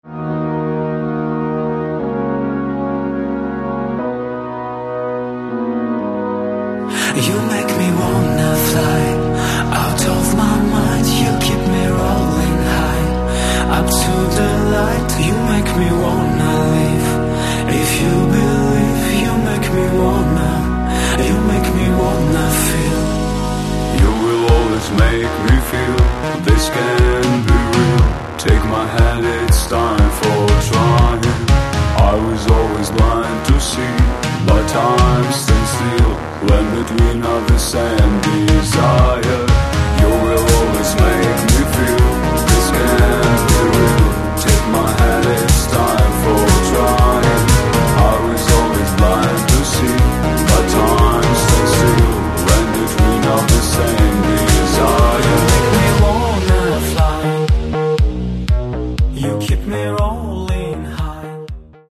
Каталог -> Поп (Легкая) -> Клубная